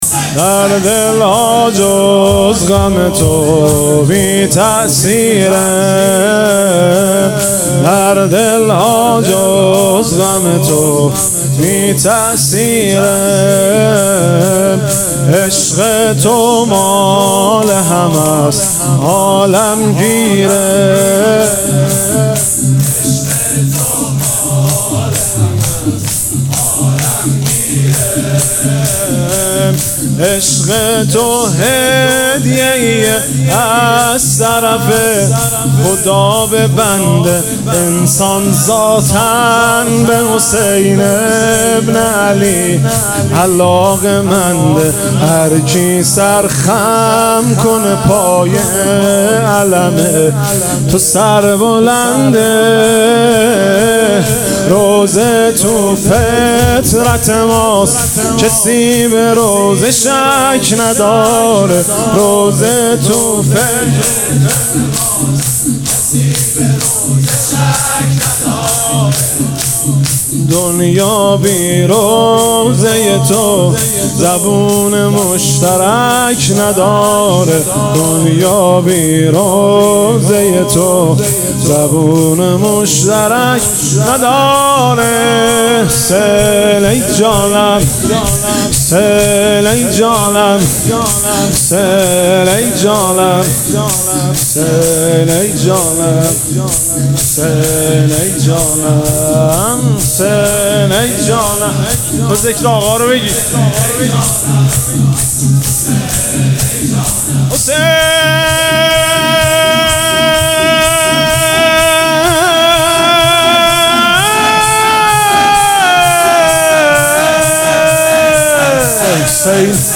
شور ـ در دل‌ها جز غم تو ـ مهدی رسولی